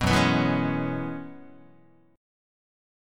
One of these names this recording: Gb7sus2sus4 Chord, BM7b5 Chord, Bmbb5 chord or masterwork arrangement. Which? Gb7sus2sus4 Chord